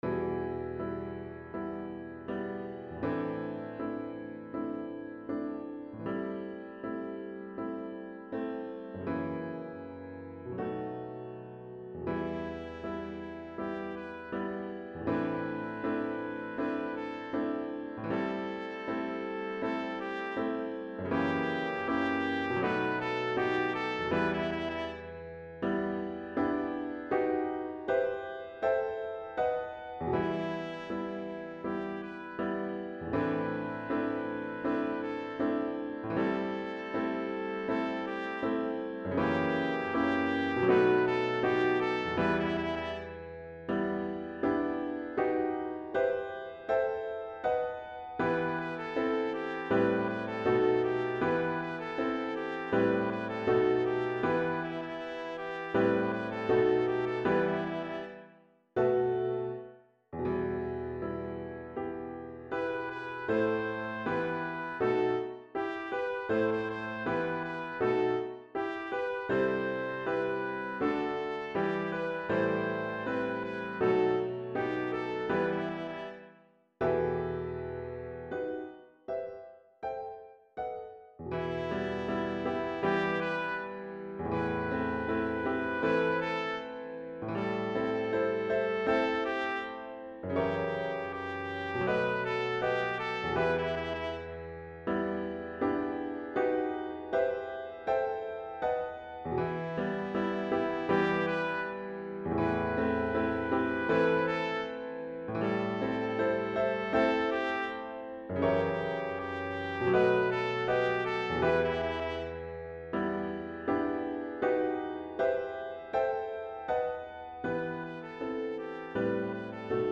Backing Track
Piano and Melody
Feeling-the-Heat-Piano-and-Melody.mp3